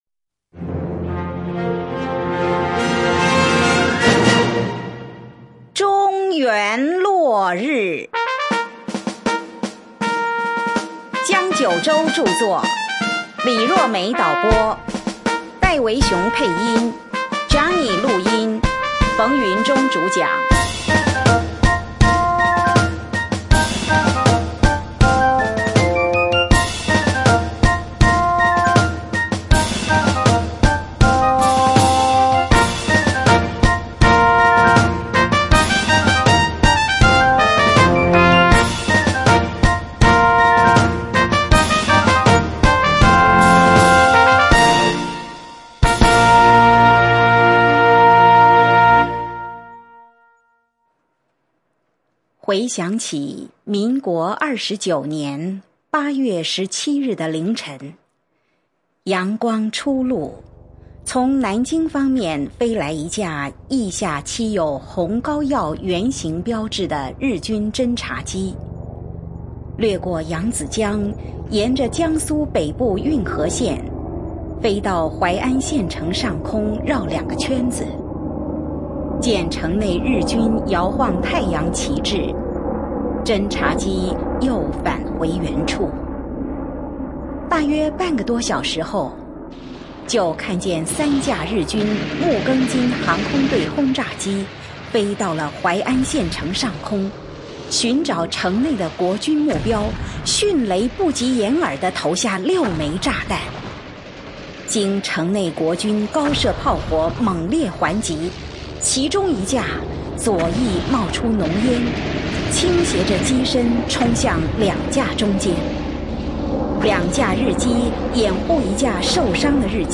中原落日｜廣播劇